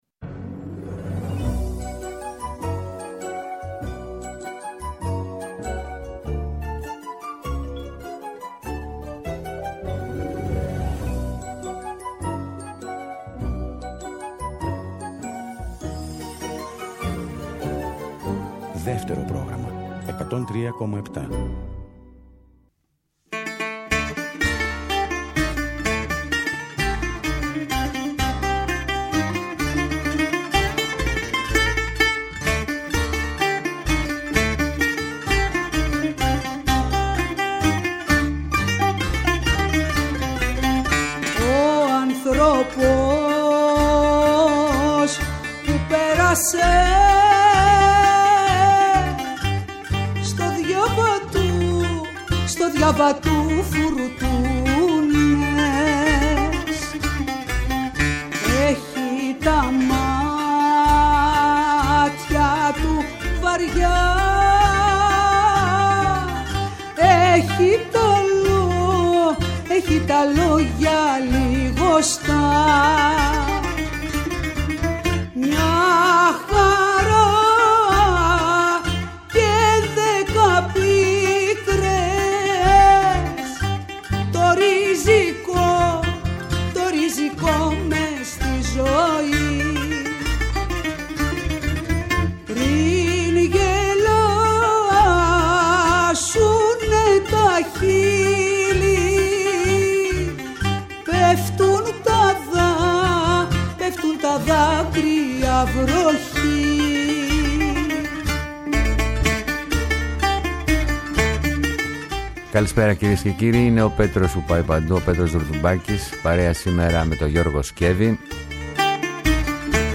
ραδιοφωνικό – μουσικό road trip ΔΕΥΤΕΡΟ ΠΡΟΓΡΑΜΜΑ